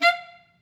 Clarinet
DCClar_stac_F4_v3_rr2_sum.wav